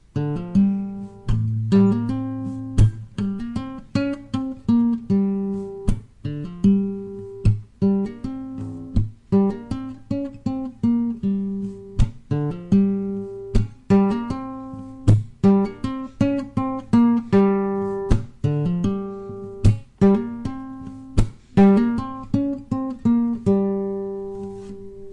描述：这是用古典（尼龙弦）吉他上的拨片演奏的短音符重复。
标签： 尼龙 古典 重复 注意 吉他
声道立体声